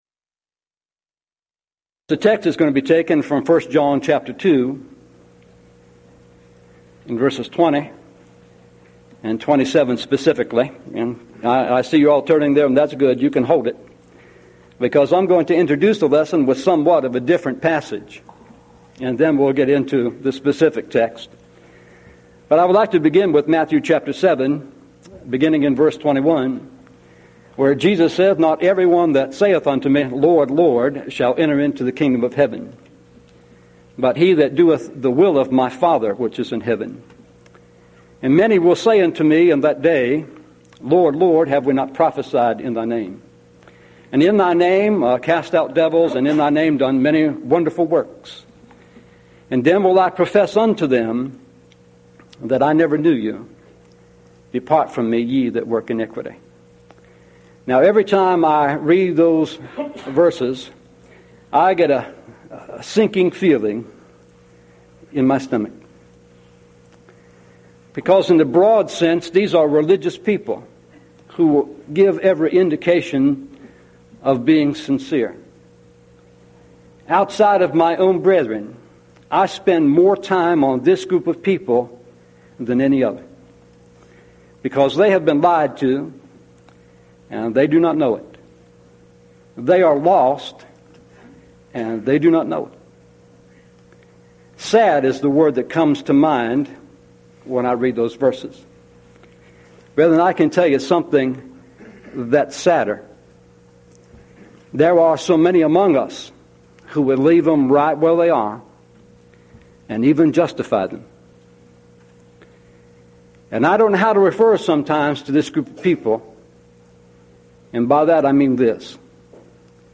Event: 1997 Mid-West Lectures Theme/Title: God The Holy Spirit